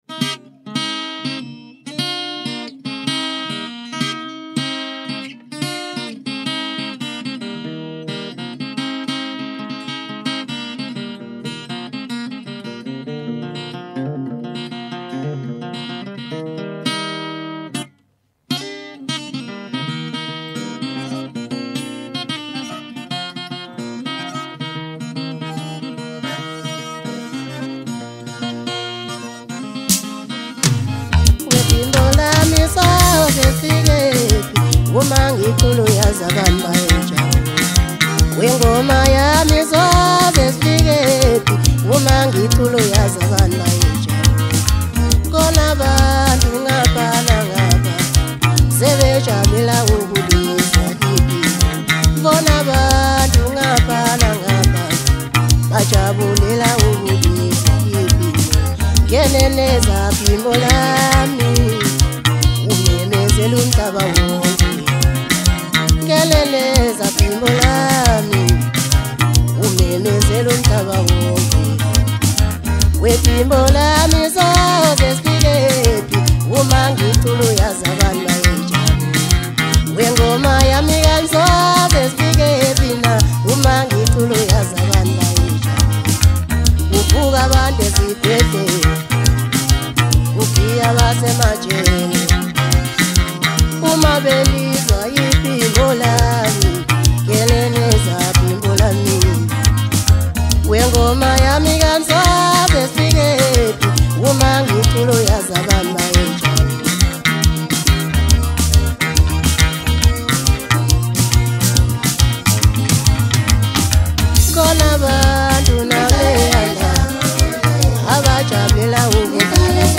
Home » Hip Hop » DJ Mix » Maskandi